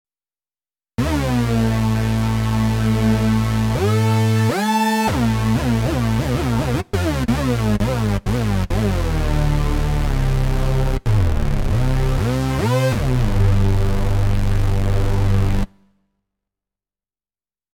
Challenge: anybody managed a half decent hoover?
That said, getting interesting results by setting user envelope pitch mod depth a little differently on OSC 1 vs OSC 2
one-voice effort